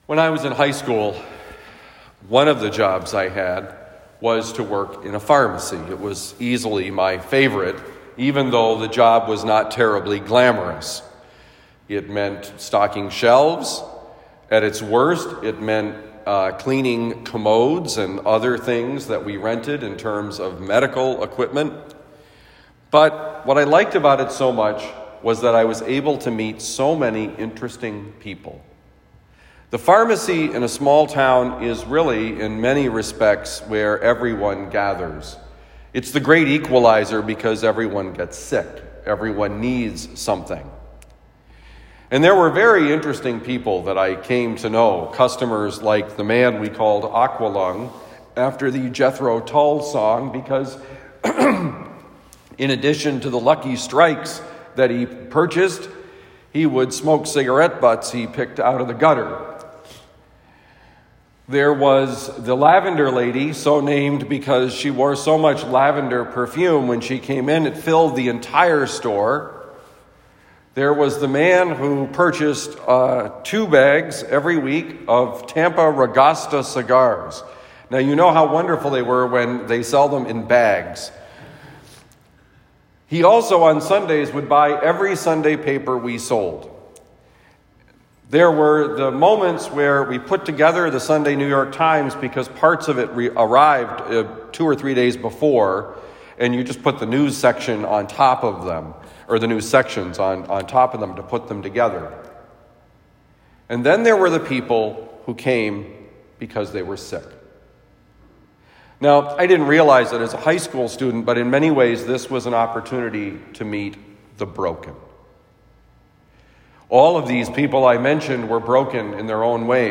Homily for Saturday, February 7, 2021
Given at Saint Dominic Priory, St. Louis, Missouri.